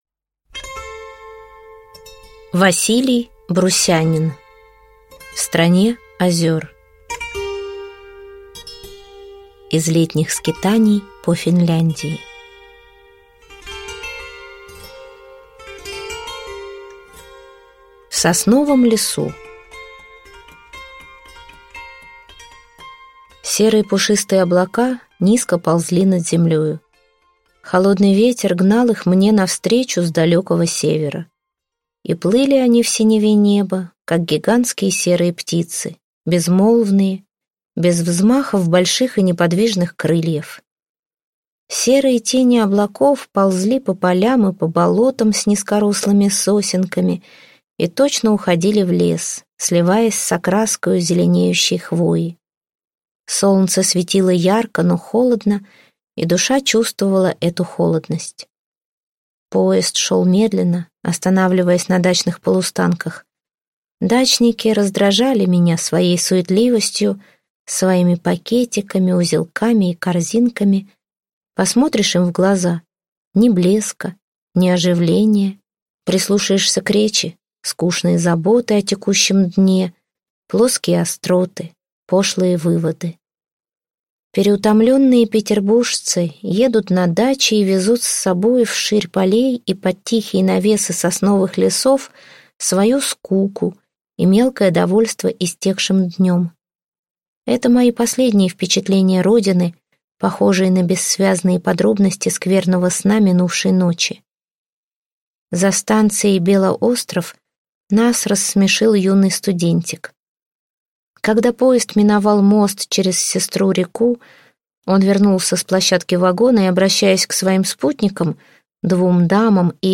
Аудиокнига В стране озёр | Библиотека аудиокниг